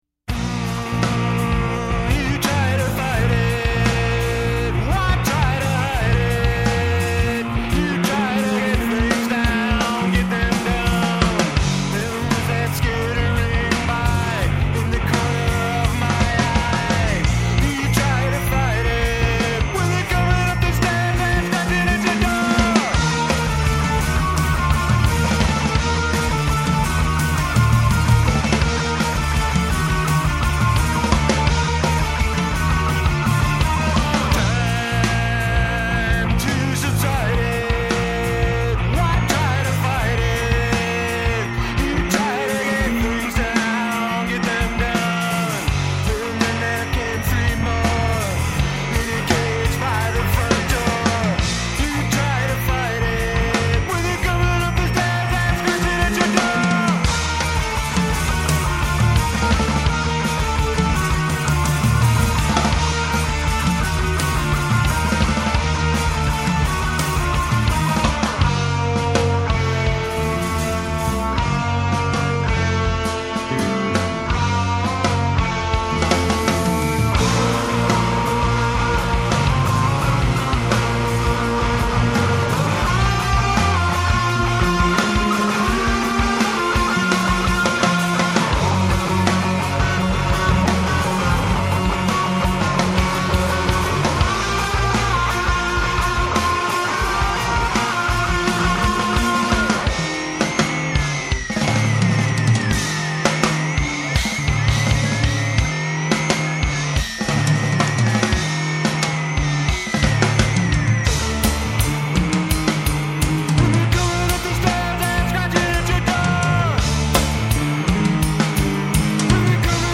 guitars and vocals
bass and vocals
drums drums and more drums